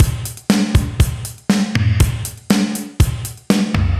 AM_GateDrums_120-03.wav